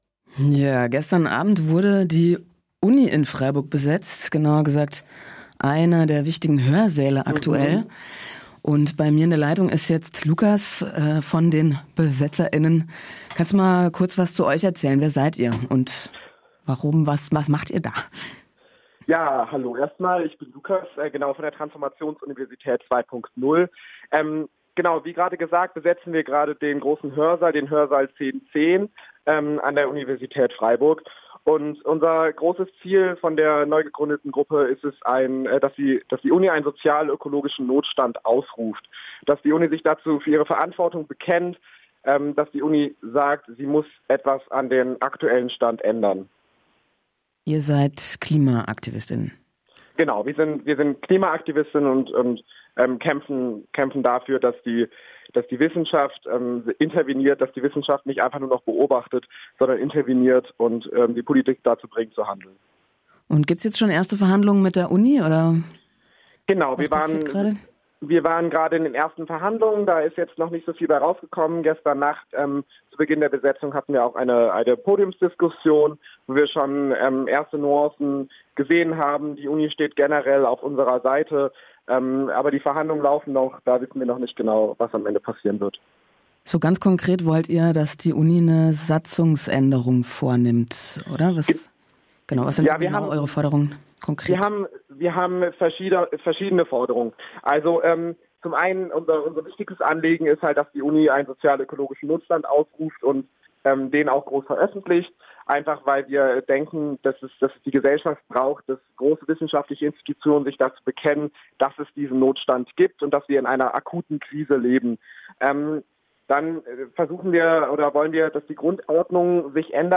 Interview und kritische Debatte um die Forderung nach dem Ausrufen des sozial-ökologischen Notstands